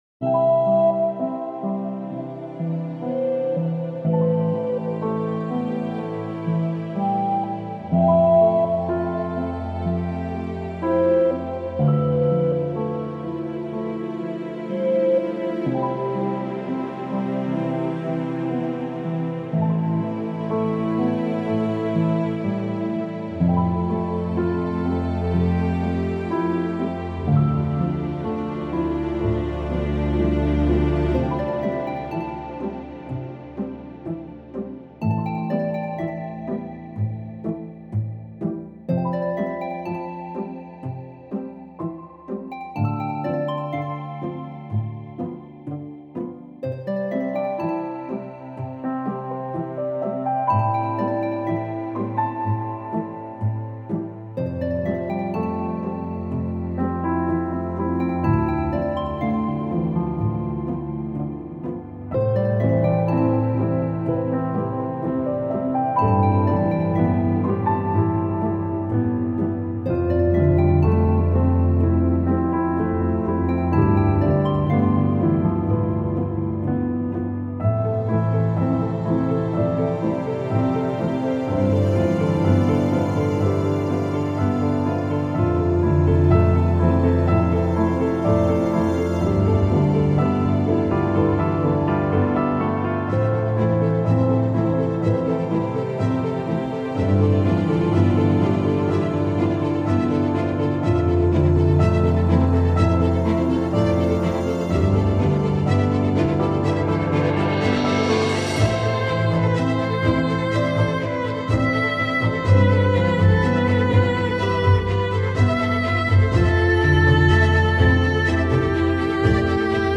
Slightly extended version
with an extra piano layer